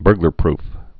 (bûrglər-prf)